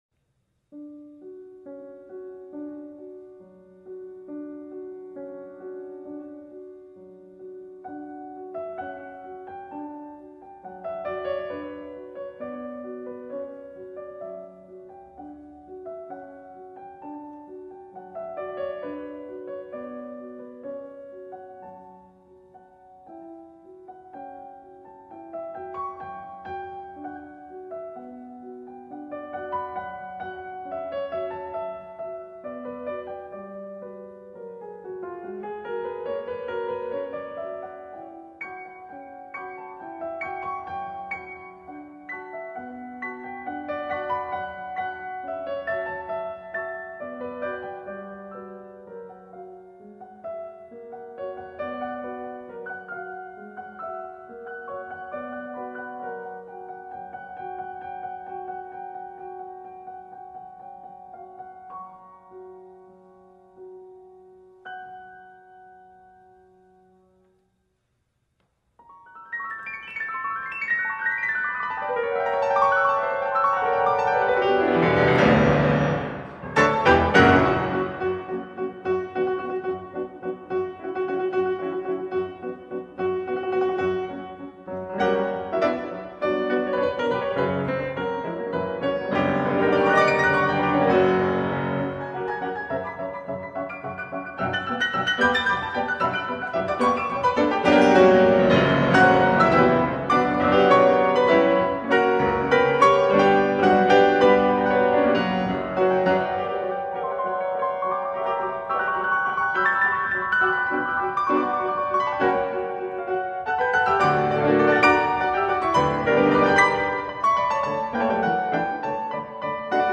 难度炸裂，效果巨佳，比赛可分章节演奏